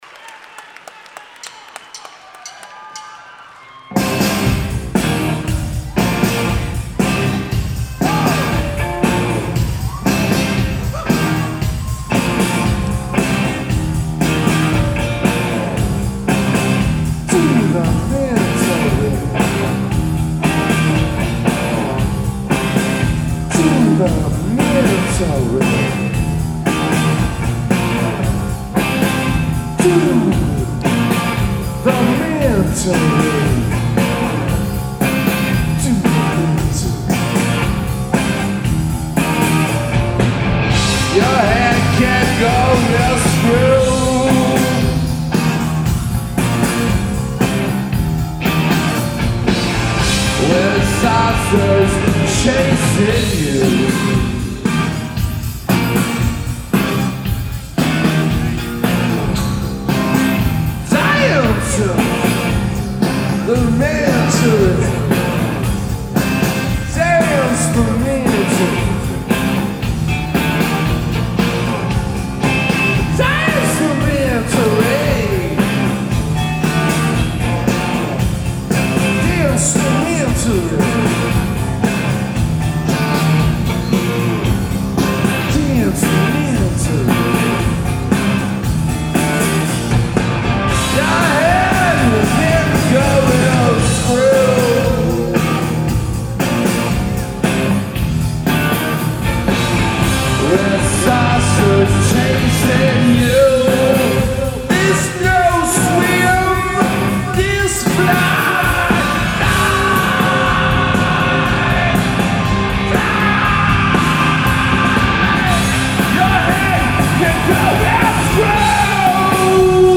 Live at the Wang Theatre / Citi Center
Audience recording
Mics = DPA 4061 > Custom BB > R09HR @ 24/96
Location = Front Row Mezzanine